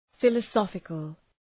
Προφορά
{,fılə’sɒfıkəl} (Επίθετο) ● φιλοσοφικός